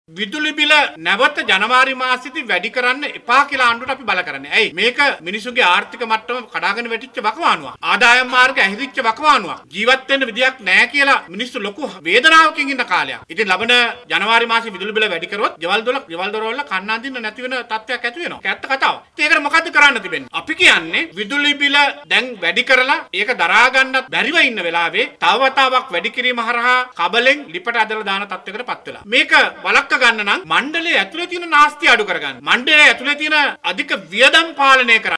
ඔහු මෙම අදහස් පළකලේ කොළොඹ පැවති මාධ්‍ය හමුවකට් එක්වෙමින් .